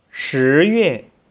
(Click on any Chinese character to hear it pronounced.
shiyue.wav